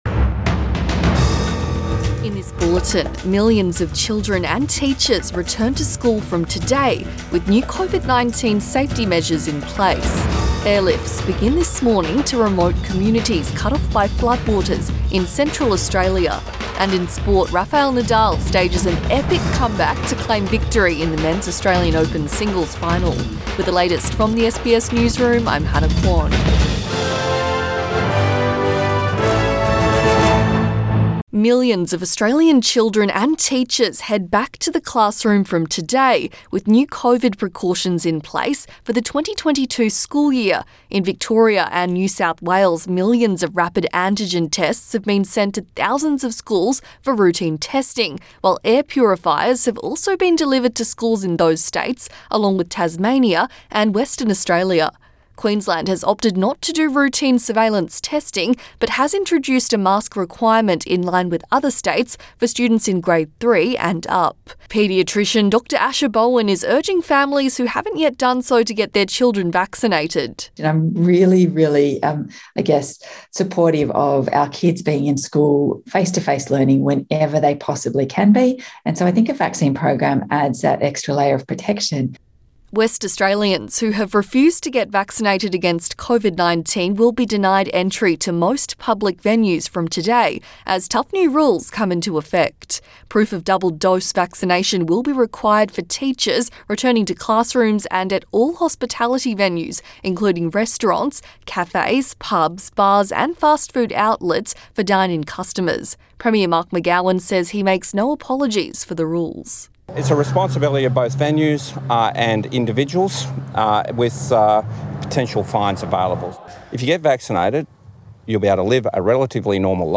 AM bulletin 31 January 2022